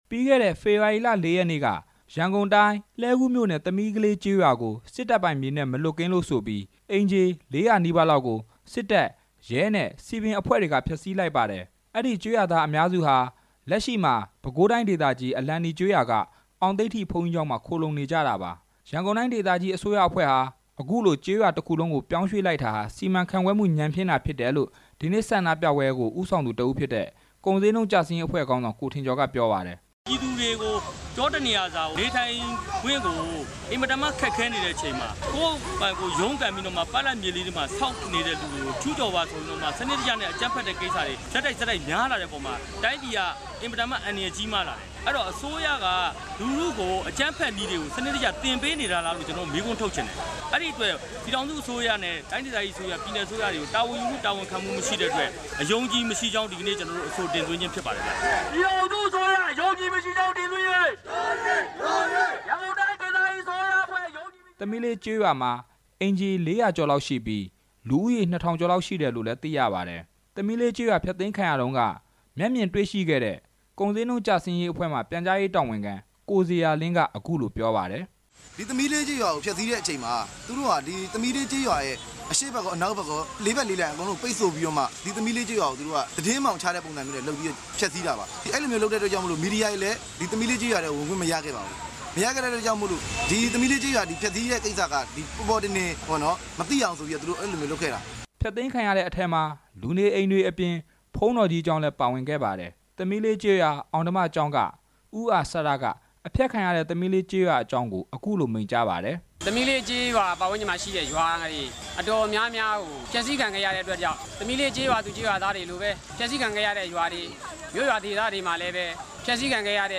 ဒီဆန္ဒပြပွဲအကြောင်း တင်ပြချက်